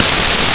WAV snaredrums